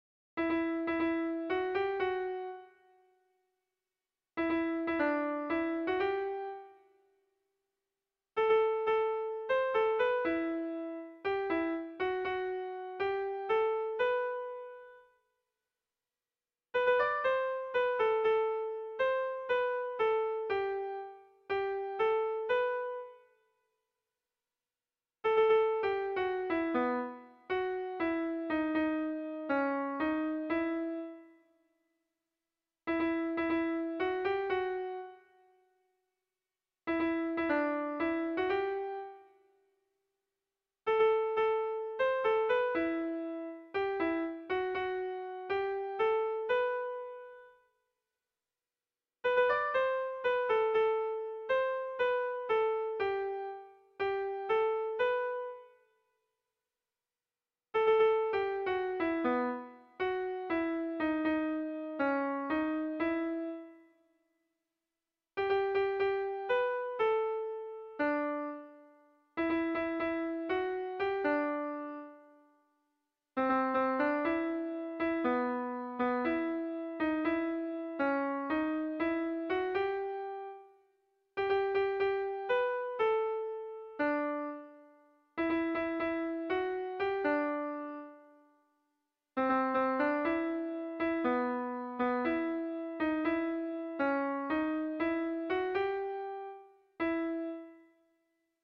Erromantzea